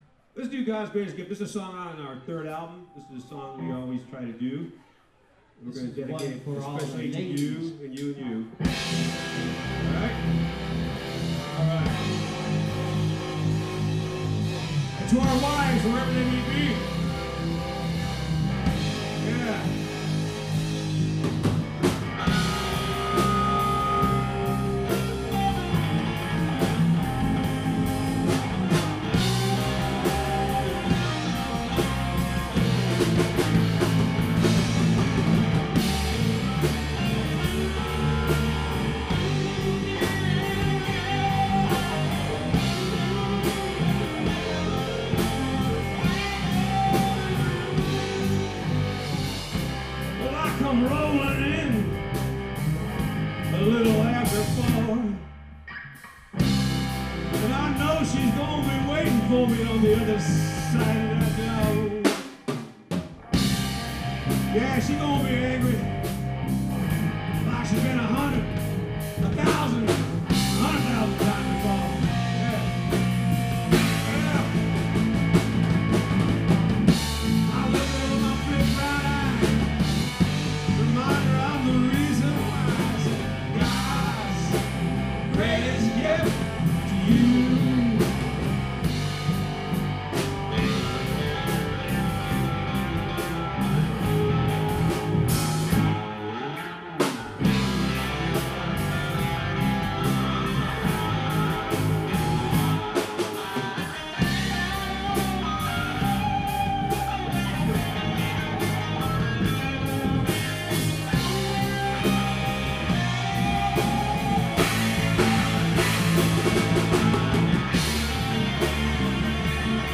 We try to record every show.